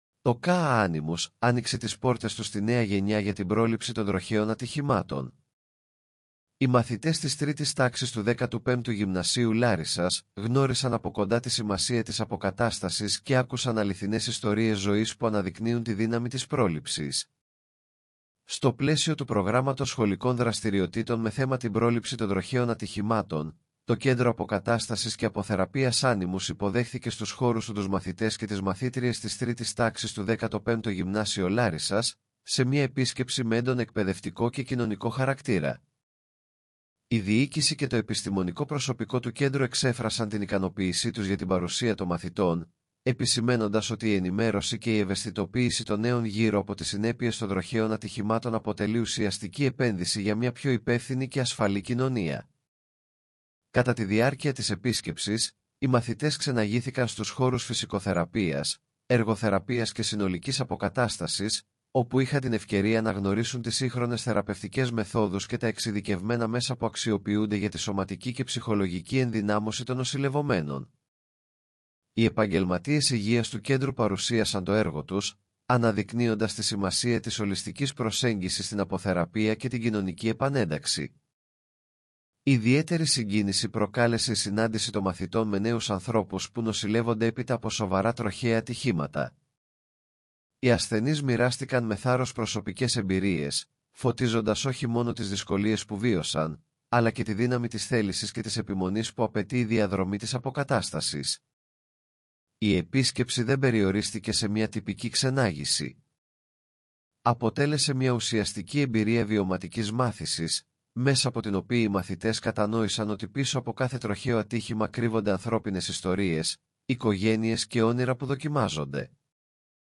AI υποστηριζόμενο ηχητικό περιεχόμενο